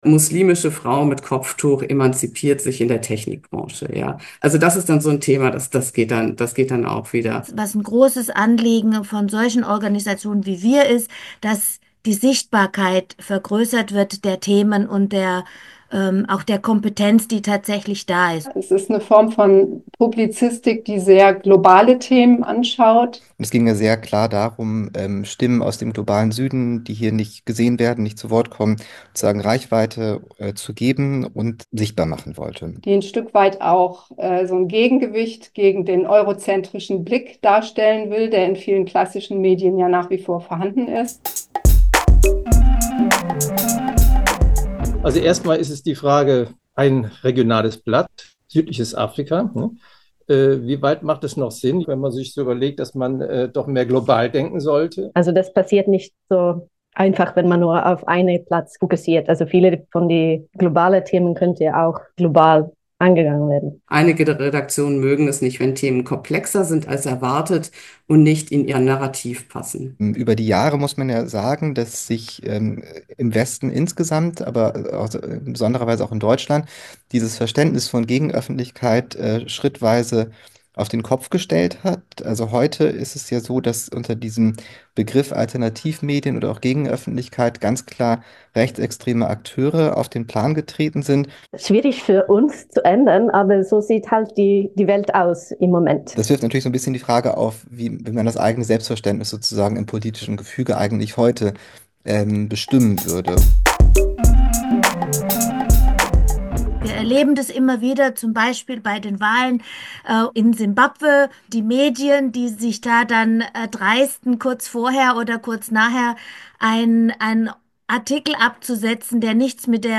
Collage von Statements aus dem Online Gespräch über Perspektiven Nord-Süd-Medienarbeit
Die Collage möchte einen kurzen Eindruck geben.